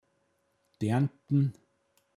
pinzgauer mundart
Deantn, f. Dienten